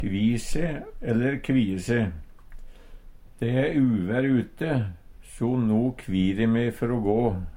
DIALEKTORD PÅ NORMERT NORSK kvi se/ kvie se grue seg Infinitiv Presens Preteritum Perfektum å kvi kvir kvidde kvidd Eksempel på bruk De e uvær ute, so no kvir e me før o gå. Hør på dette ordet Ordklasse: Verb Attende til søk